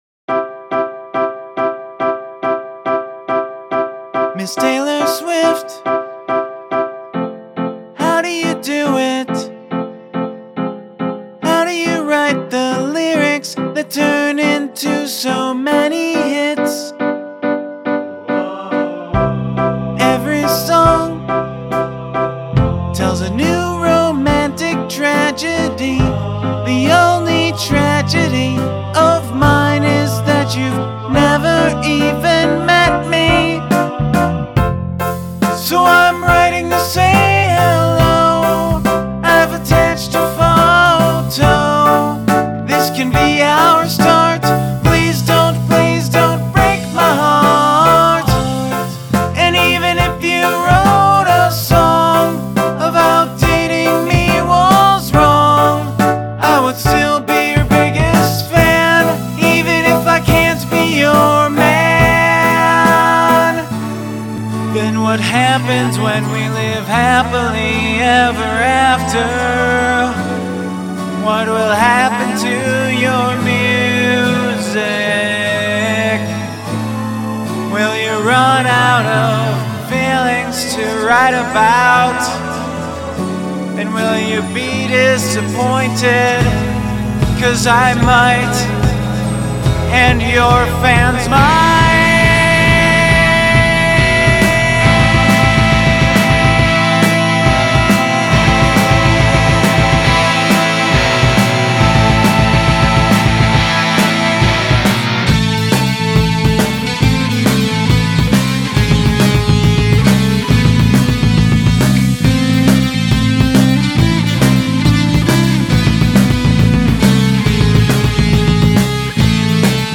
Three part song in less than three minutes